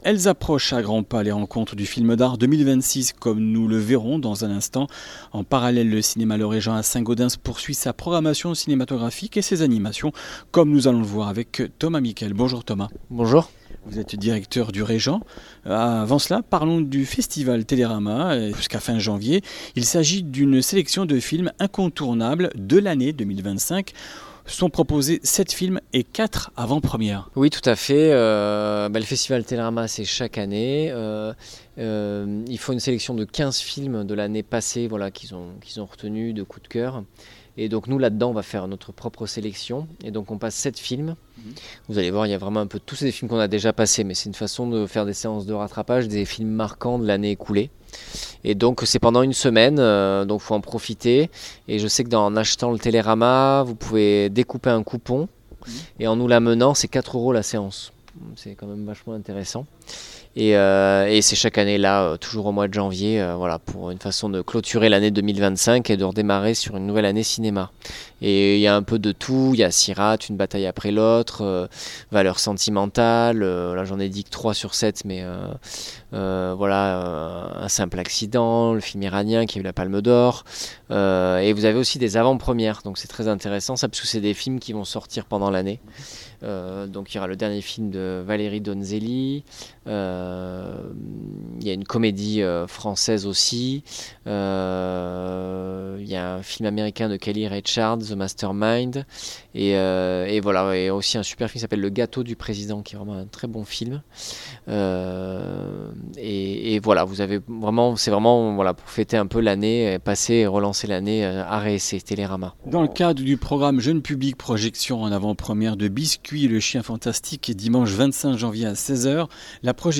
Comminges Interviews du 22 janv.
Une émission présentée par